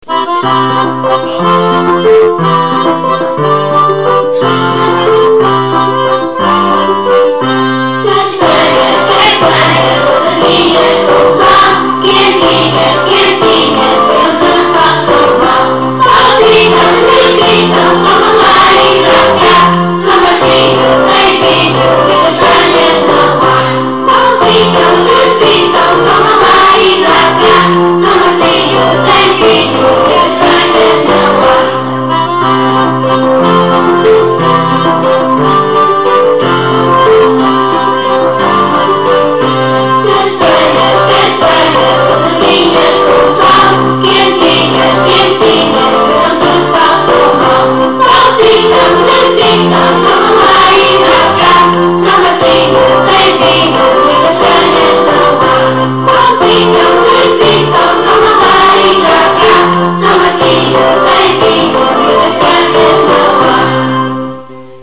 Duas musicas que os alunos cantaram: “Castanhas” e “Uma, duas, três castanhas” (uma vez que os alunos também estão a dar os números até 10…)